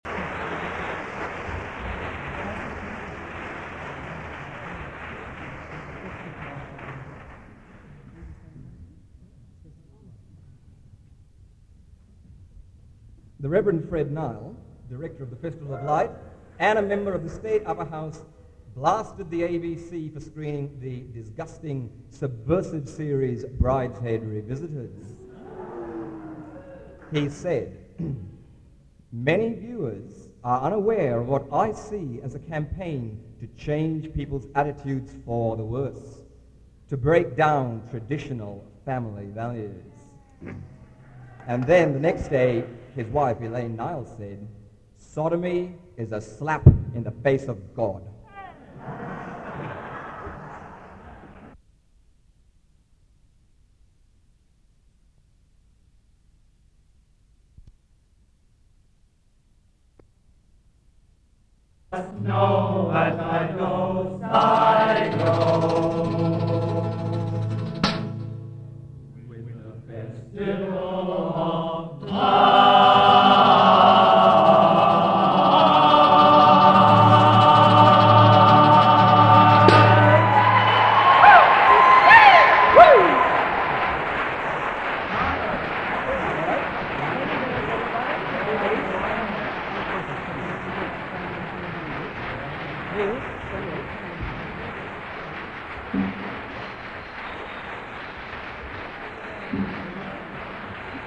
1982 Sydney Concert